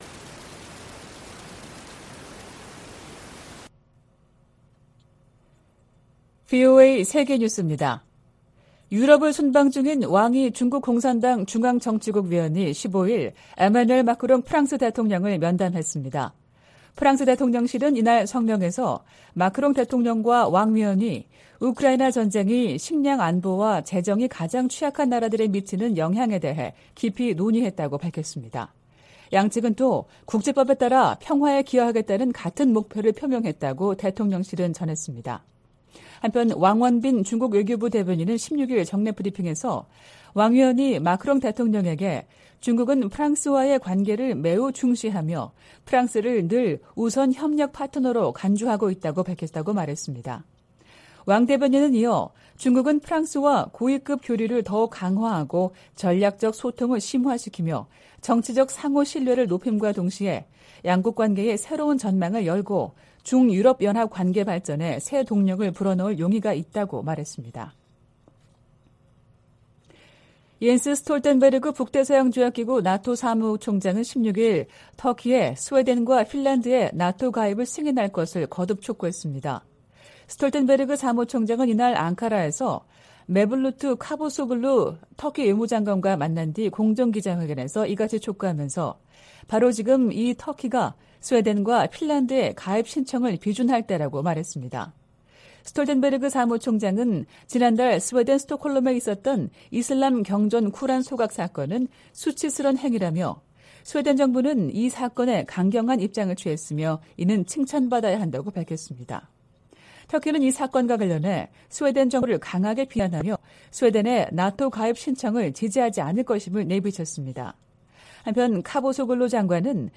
VOA 한국어 '출발 뉴스 쇼', 2023년 2월 17일 방송입니다. 한국 정부의 국방백서가 6년만에 북한 정권과 군을 다시 적으로 명시했습니다. 미 국무부의 웬디 셔먼 부장관이 한국, 일본과의 외교차관 회담에서 북한의 도발적인 행동을 규탄하고 북한의 외교 복귀를 촉구했습니다. 미 하원에서 다시 재미 이산가족 상봉 결의안이 초당적으로 발의됐습니다.